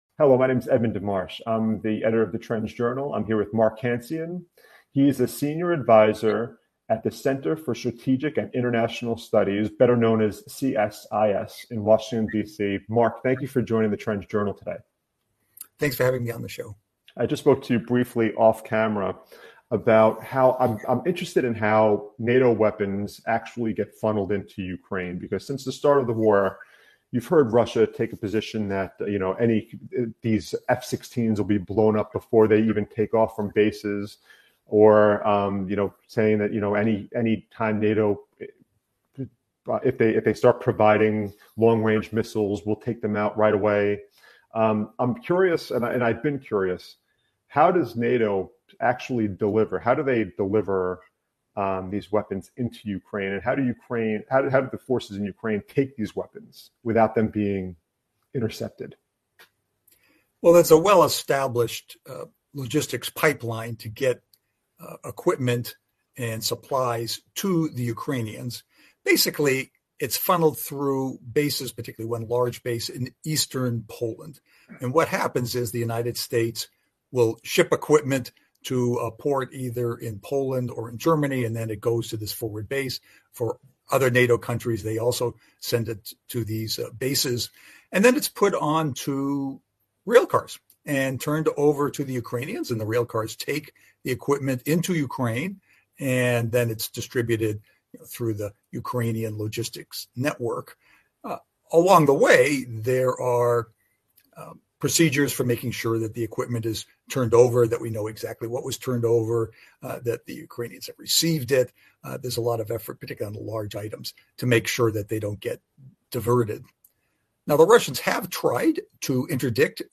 The interview also discussed the potential for NATO to confront Russia in Ukraine, the U.S.’s role in supporting Ukraine, and the challenges Ukraine faces in terms of weapons and manpower.